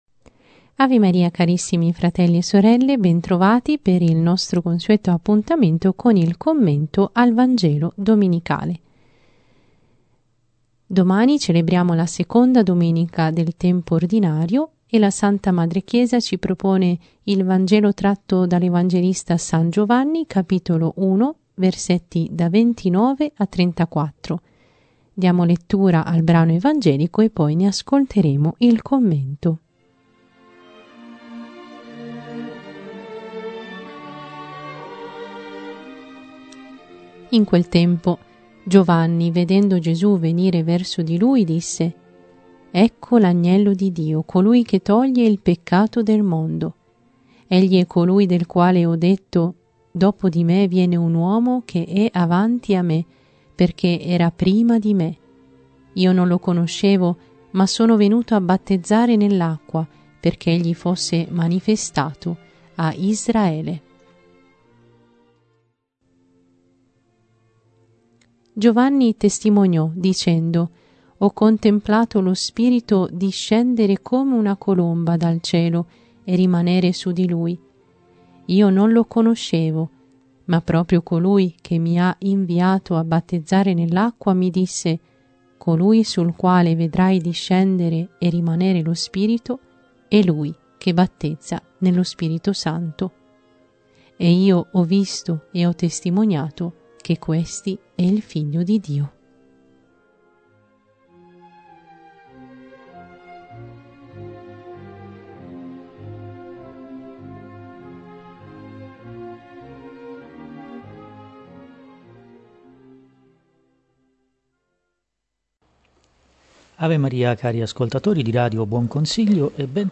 Genere: Commento al Vangelo.